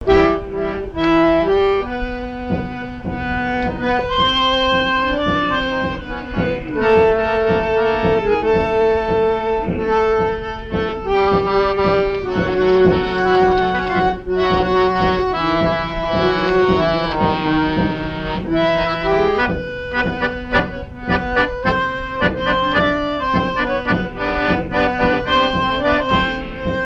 Talmont-Saint-Hilaire
danse : tango
Vie de l'orchestre et son répertoire, danses des années 1950
Pièce musicale inédite